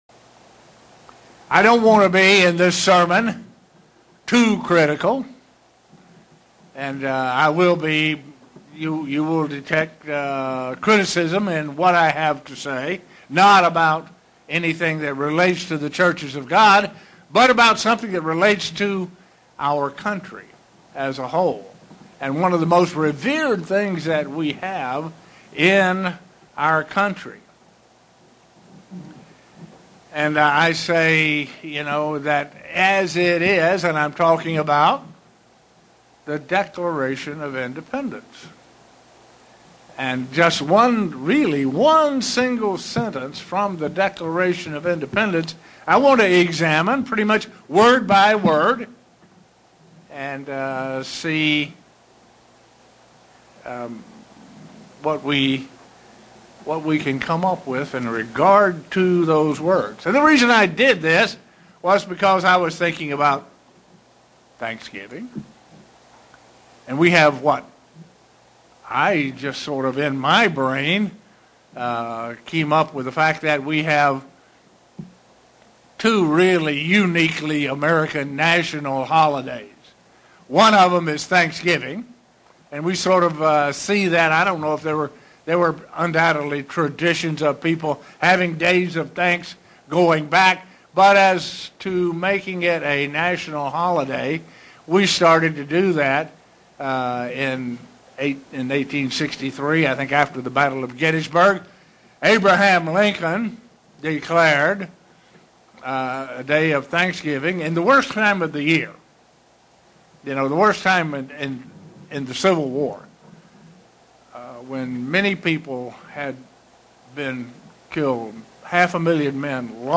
Given in Buffalo, NY
Print A Biblical view of the Declaration of Independence. sermon Studying the bible?